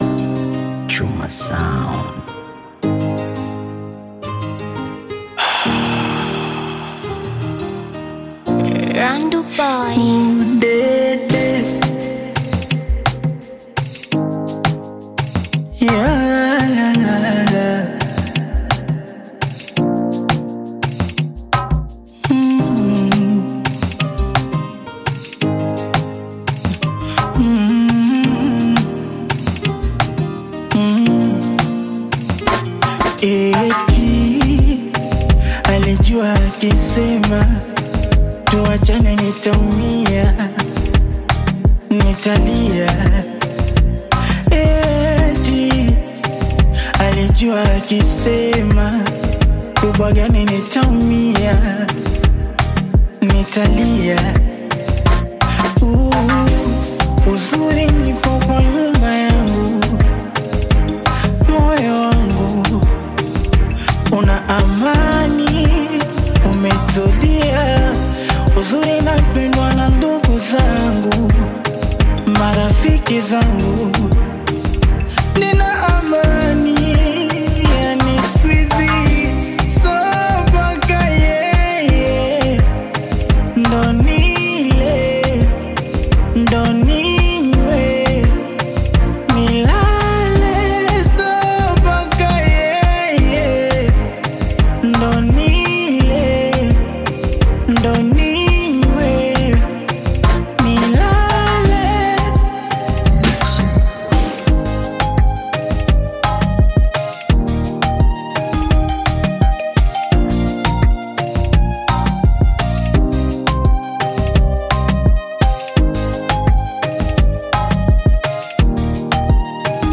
Genre: Bongo Flava